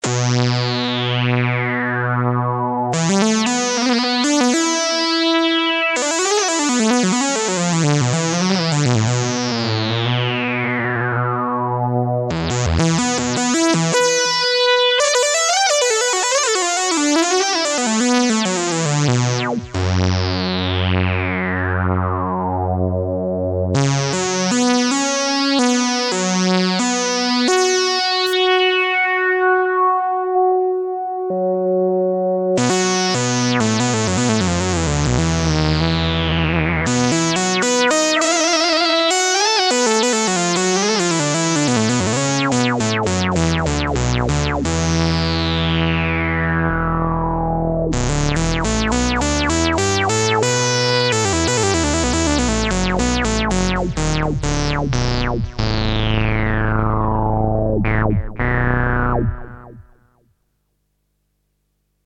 next clips featuring just the suboscillators - their waveforms are more conventional than the main vcdo output, and lend themselves to some subtractive synthesis.
2 x waverider sub oscillators through my system x filter